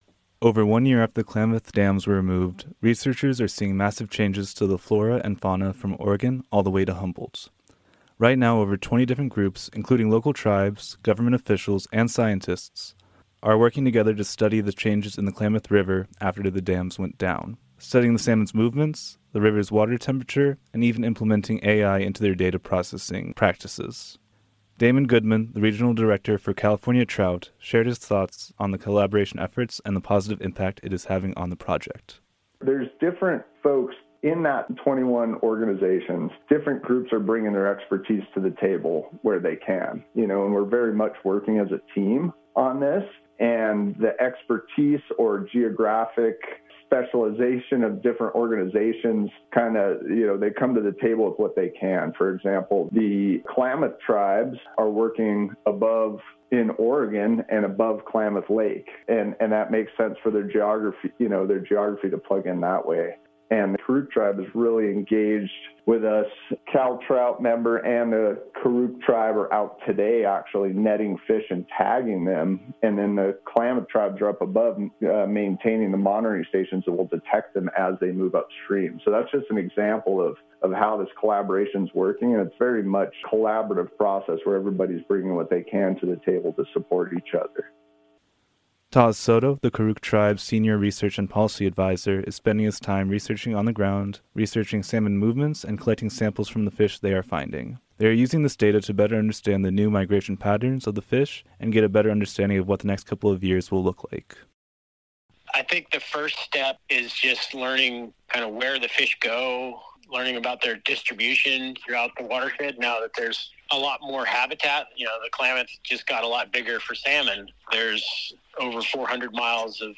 KMUD News